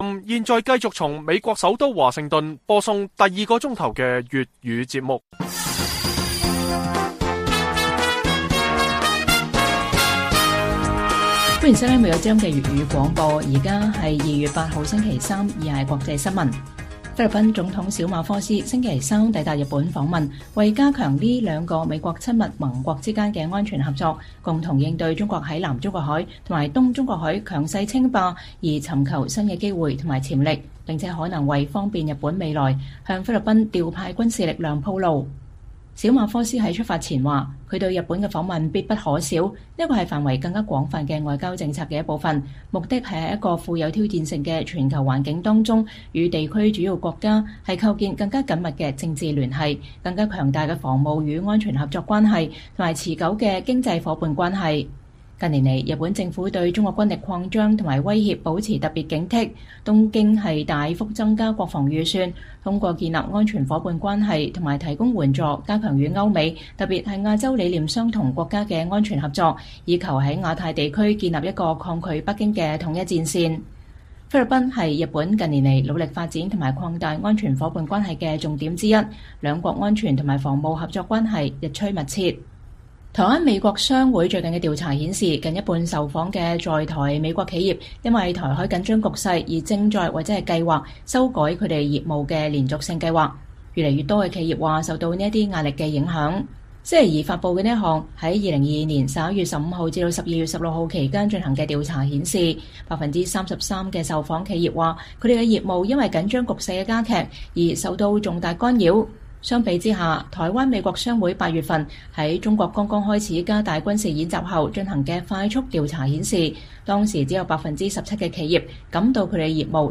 粵語新聞 晚上10-11點：菲律賓總統抵達日本訪問